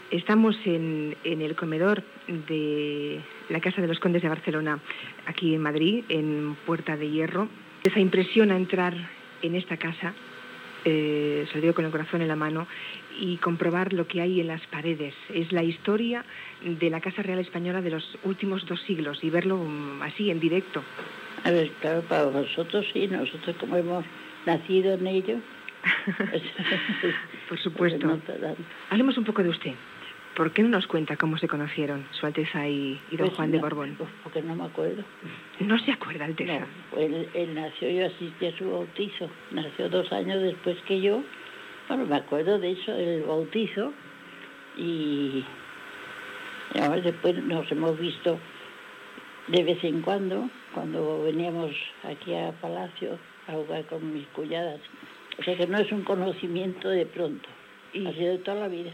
Fragment d'una entrevista de Julia Otero a la "condesa de Barcelona", María de las Mercedes Borbón y Orleans, feta a casa seva